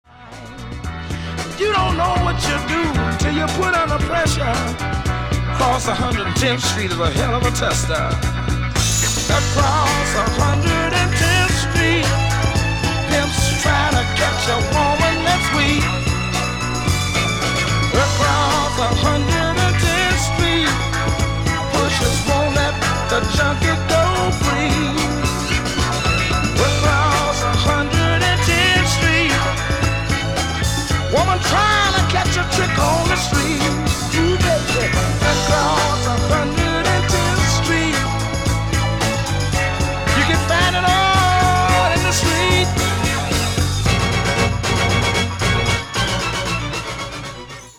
• Качество: 320, Stereo
Blues
Ретро музыка из хорошего фильма